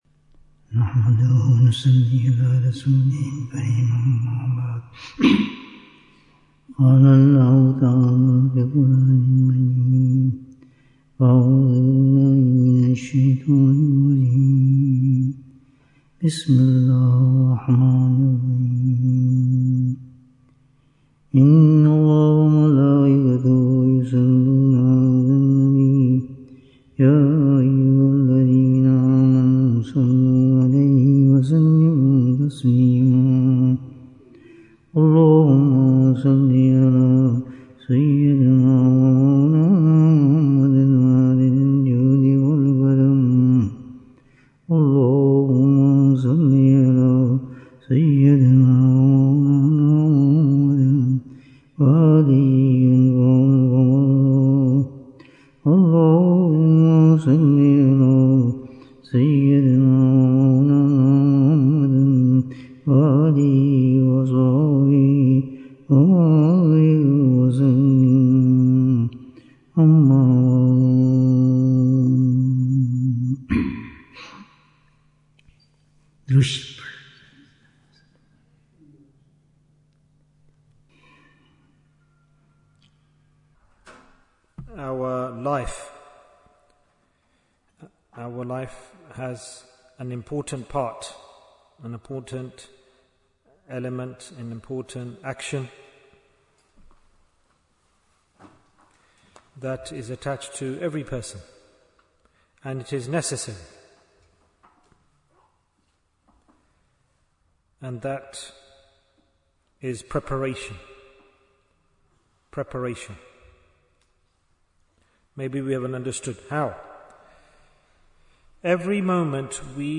Appreciate Blessings Before they Perish Bayan, 110 minutes6th November, 2025